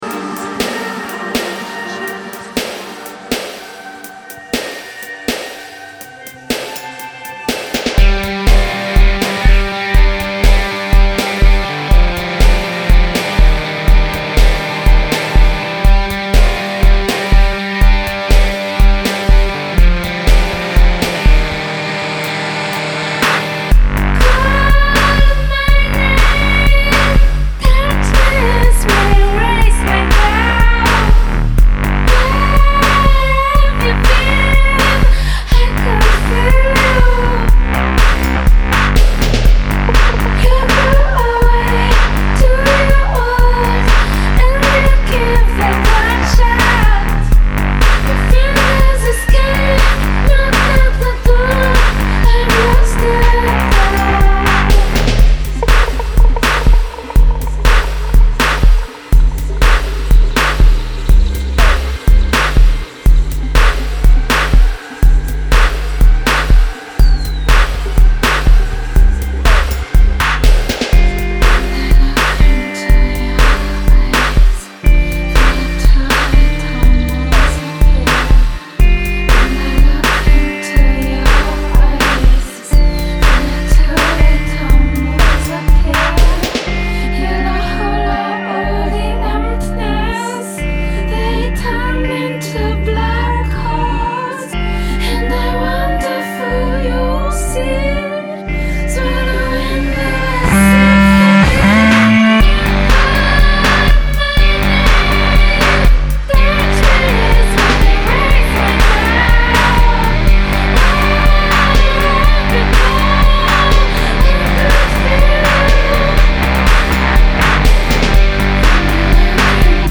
trip hop
sounds like a very bad night turning for the worse